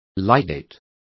Complete with pronunciation of the translation of ligating.